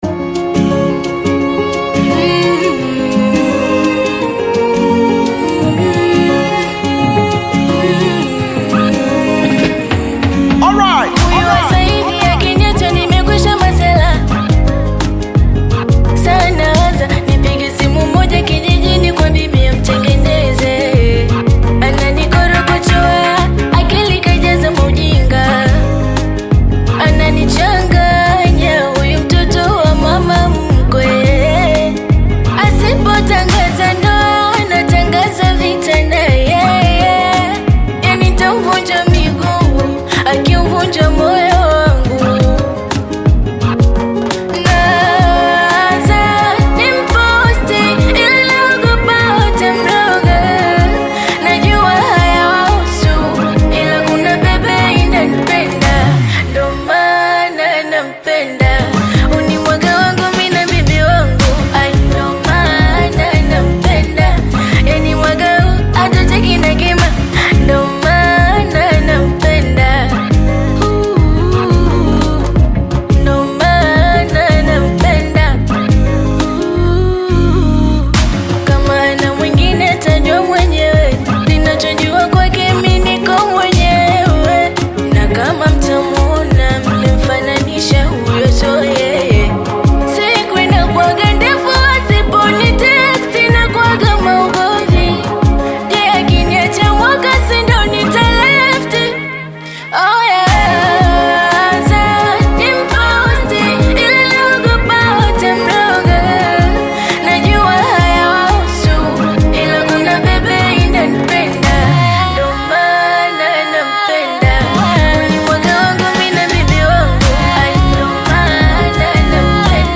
Tanzanian upcoming bongo flava artist, singer and songwriter
African Music